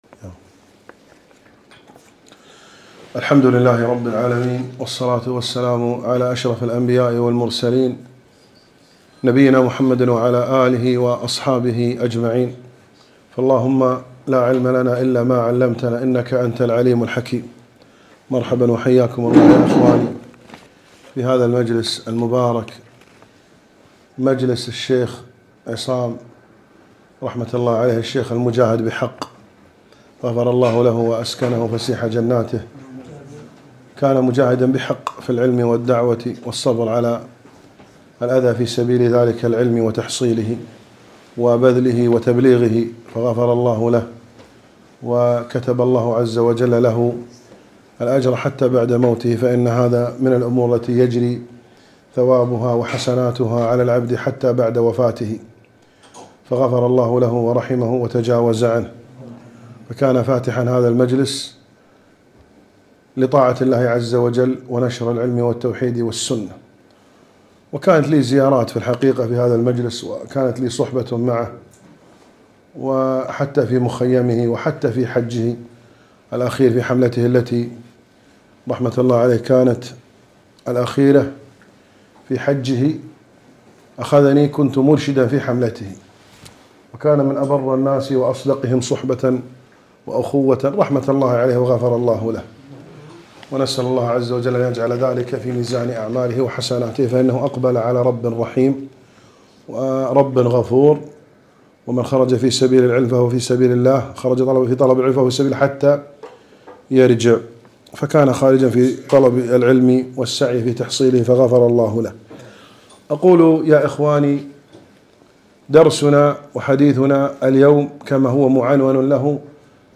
محاضرة - الحياء من شعب الإيمان